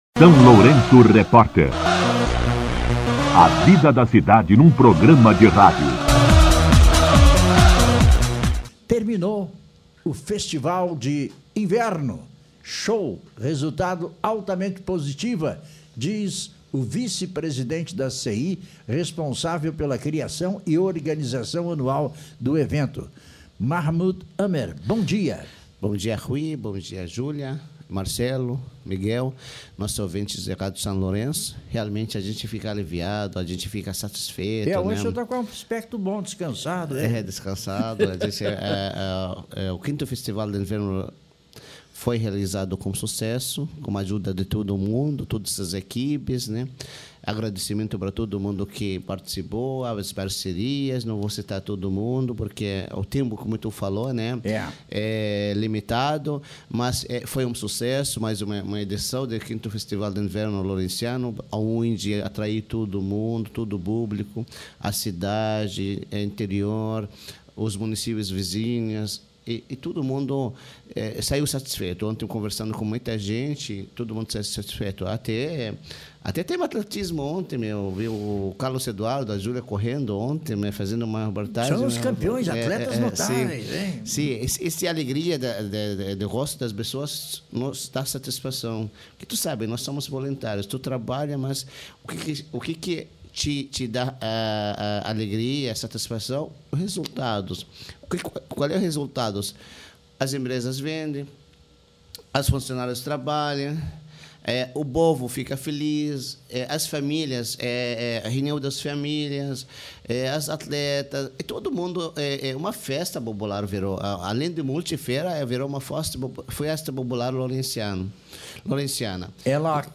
em entrevista ao SLR RÁDIO.